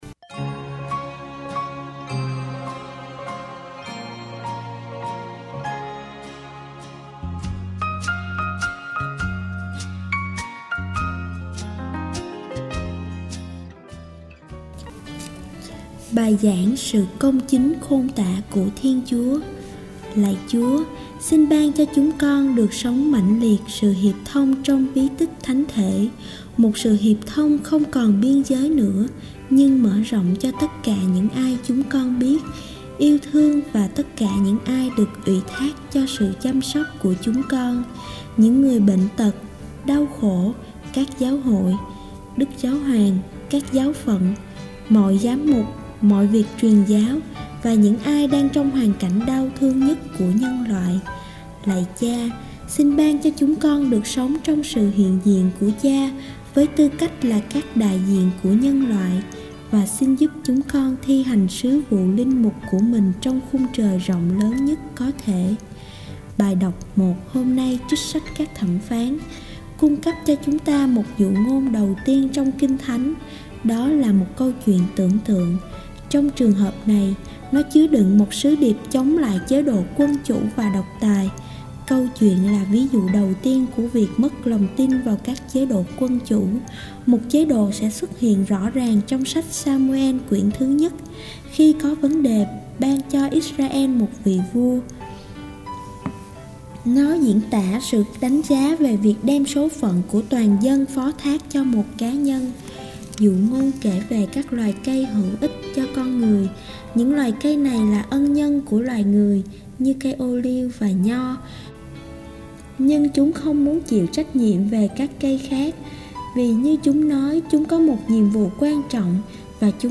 09. Bai giang su cong chinh khon ta cua Thien Chua.mp3